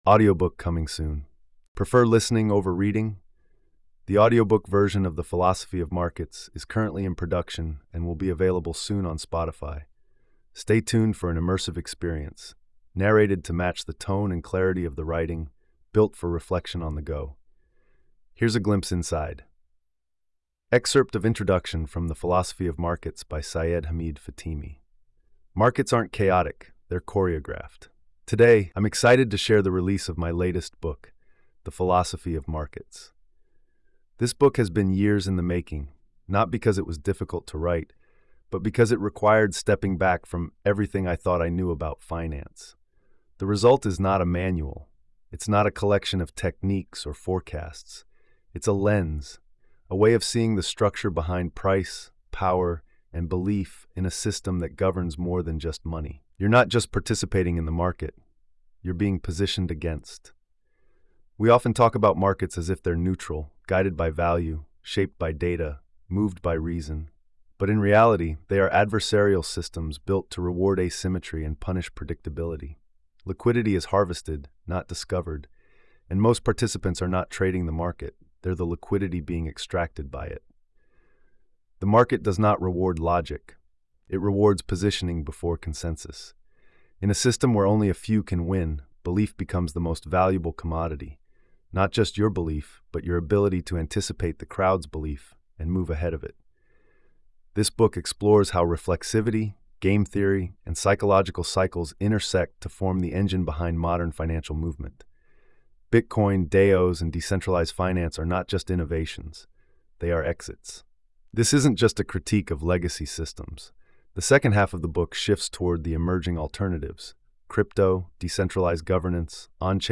🎧 Audiobook Coming Soon
Stay tuned for an immersive experiencenarrated to match the tone and clarity of the writing, built for reflection on the go.